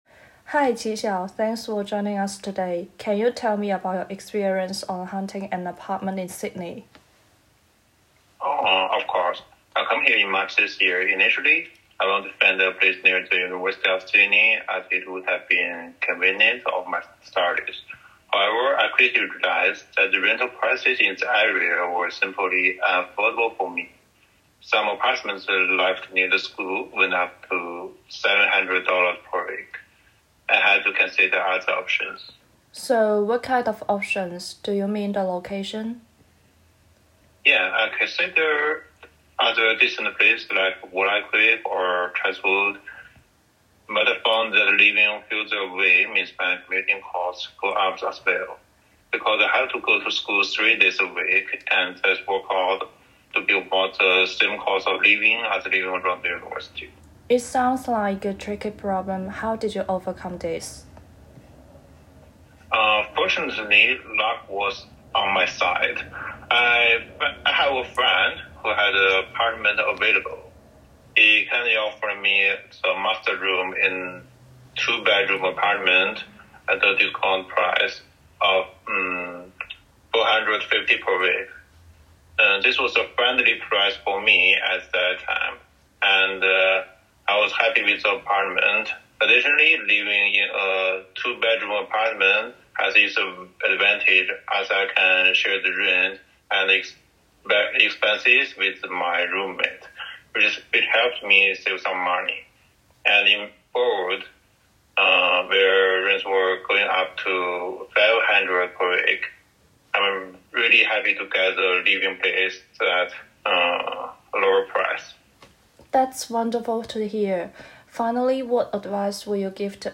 Audio-interview.m4a